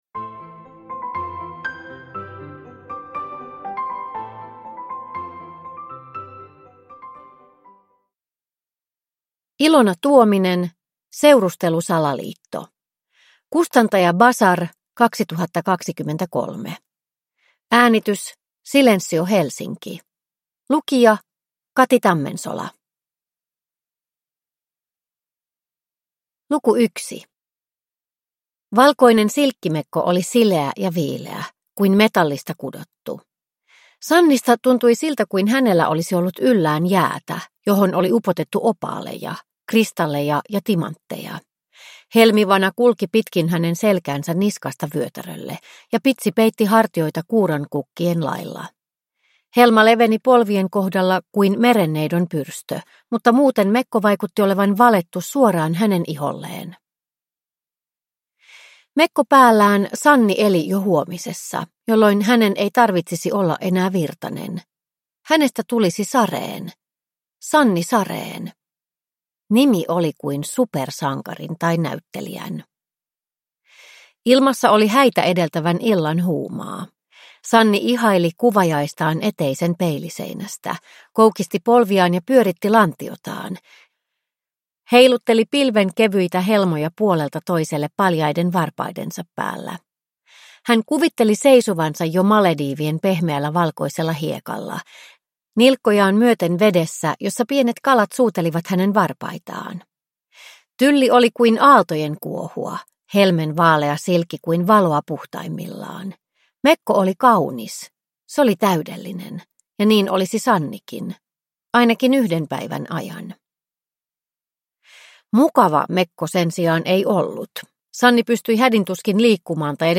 Seurustelusalaliitto – Ljudbok – Laddas ner